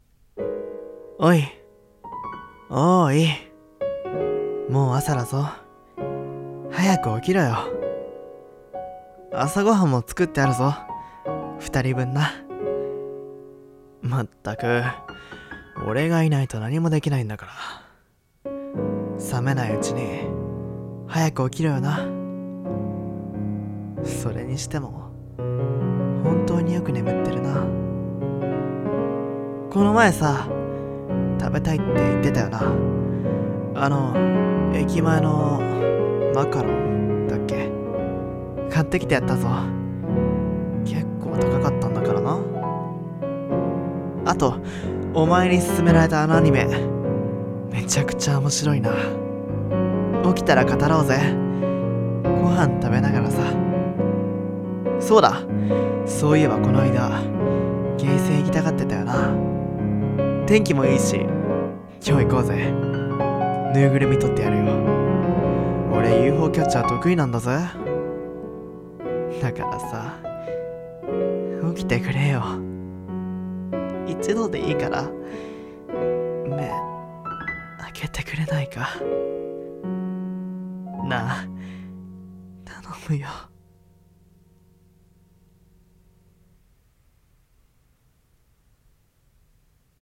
【声劇】眠っている、あなたへ。